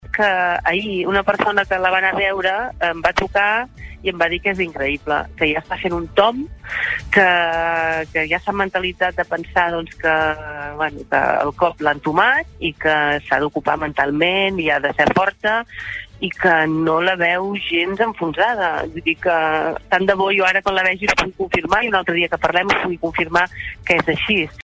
Montse Bassa ha passat avui pel Supermatí de Ràdio Capital